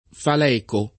[ fal $ ko ]